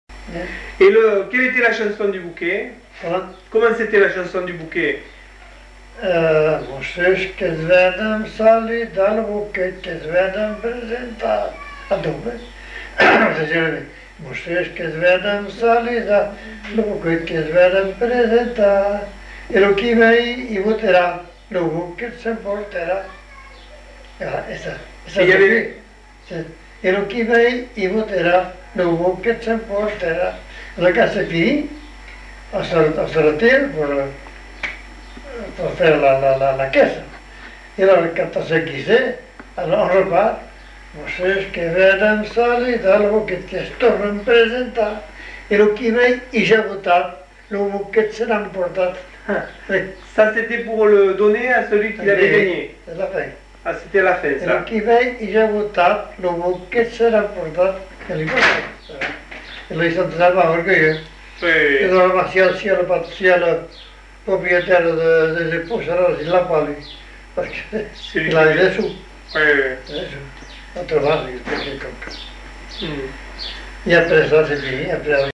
Aire culturelle : Petites-Landes
Genre : chant
Effectif : 1
Type de voix : voix d'homme
Production du son : chanté
Précisions sur le contexte dans lequel ce chant était chanté en fin de séquence.